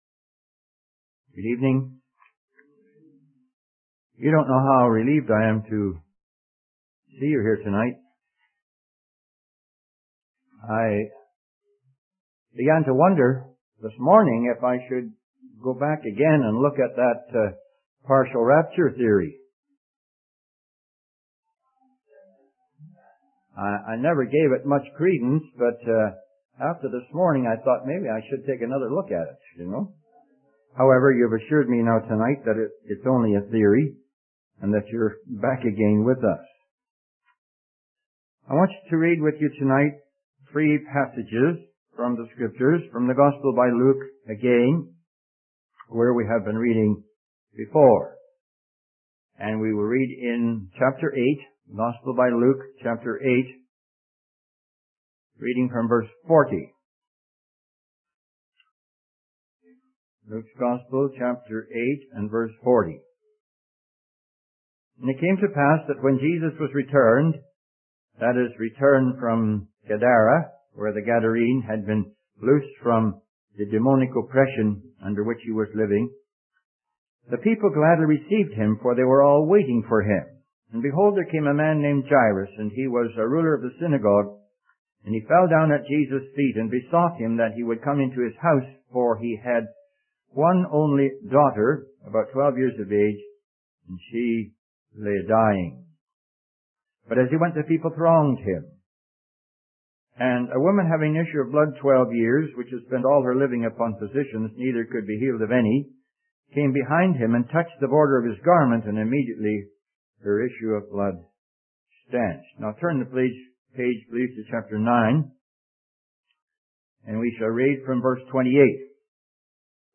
In this sermon, the preacher discusses the significance of Jesus' words about his impending death.